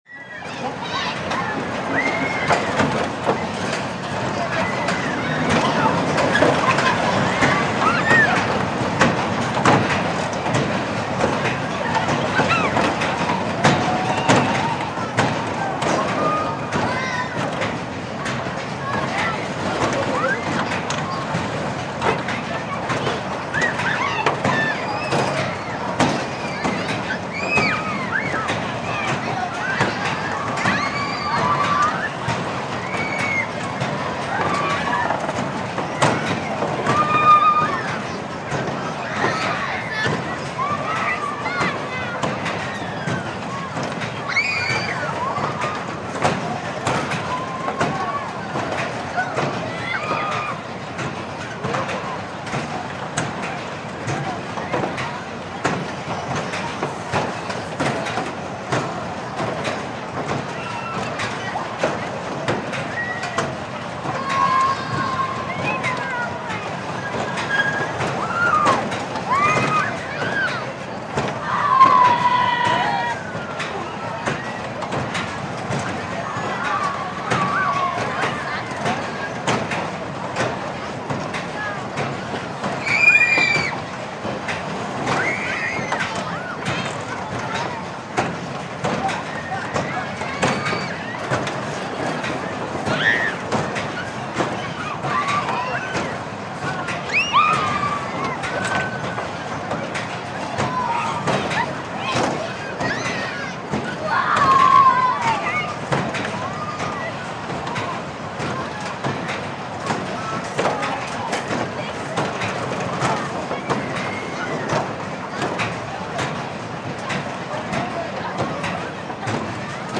Ride; Carnival Ride Operates With Metallic Clanks And Passenger Screams.